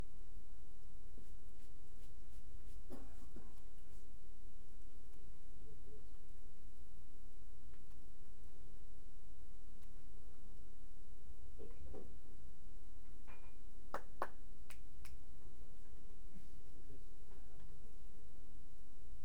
Please find the attached recorded audio below in that very last clap sound is there
Recording WAVE '/userdata/audio_test.wav' : Signed 32 bit Little Endian, Rate 96000 Hz, Stereo